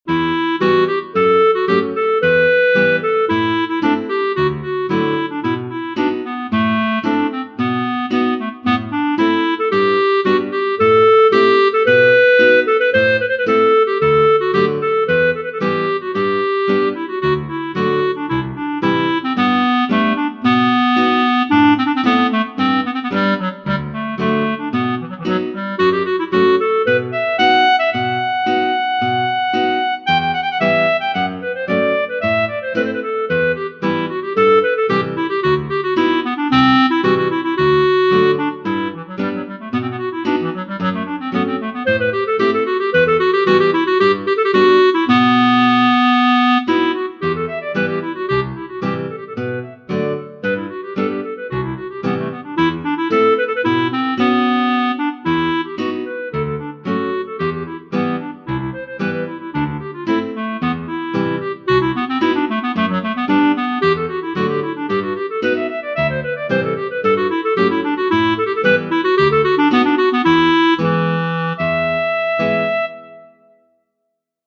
"Freedom of Speech" - Solo for clarinet/Viola/Accordion
I made better articulation and i added simple guitar background for it.